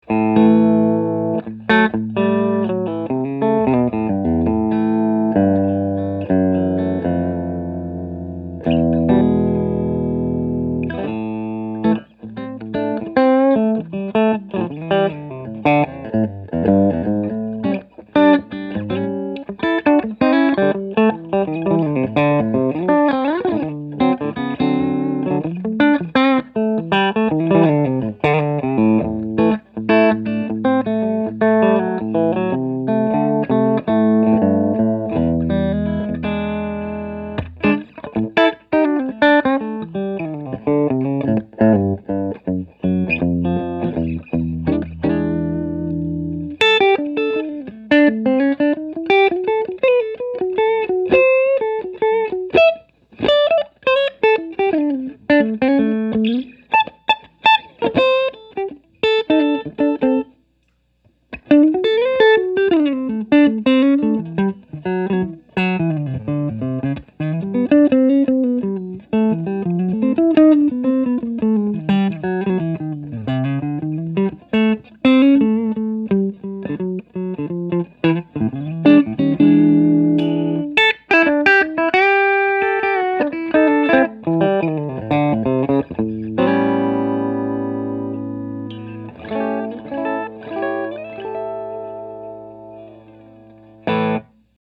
Enter this 2013 Paul’s Guitar: McCarty-thickness body with narrow 408 pickups, “brushstroke” bird inlays, and a newly designed stop tail bridge.
PRS_Pauls_Guitar_humbuckers_ds.mp3